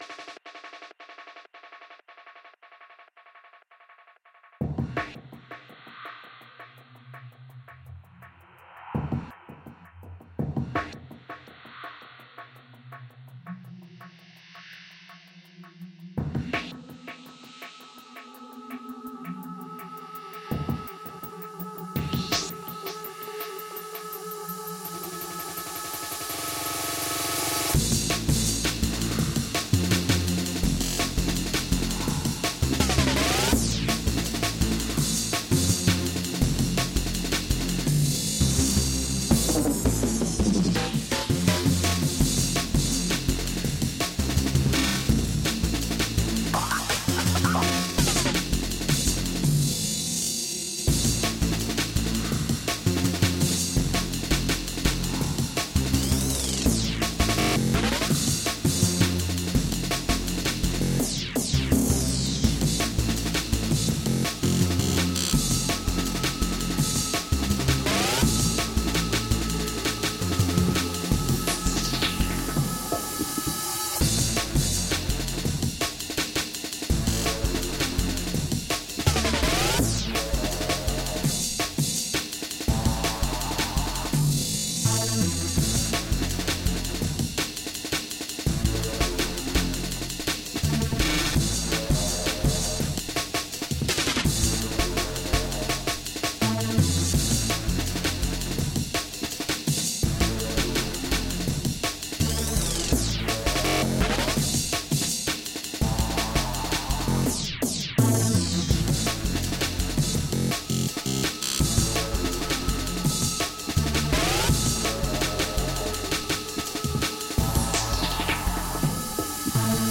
Finally, here is an example of a track I have made to illustrate how these techniques can sit in a full composition.